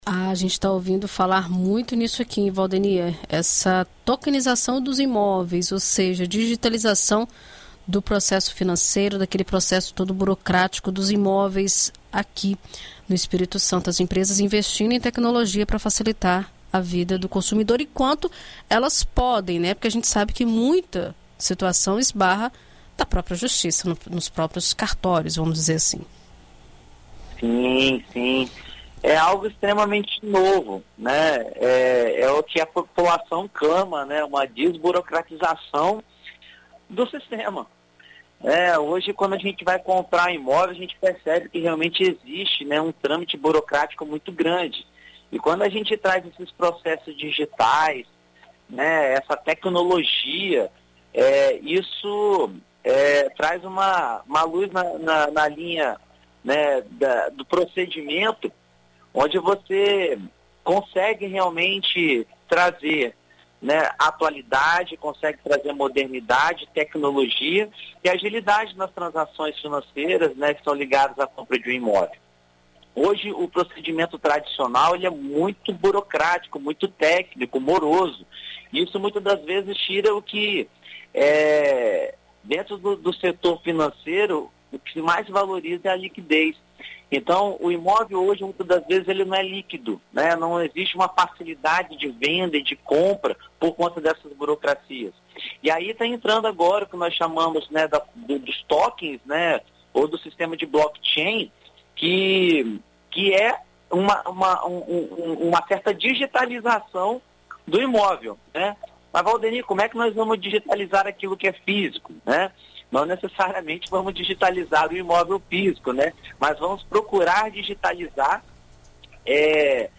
O movimento de digitalização dos processos está encarando avanços também no mercado imobiliário. Chamado de tokenização, o investimento a partir de fontes de transação digitais vem ganhando espaço e passou a ser uma forma de desburocratização. Na coluna Seu Imóvel desta terça-feira (09), na BandNews FM Espírito Santo, o advogado e especialista em mercado imobiliário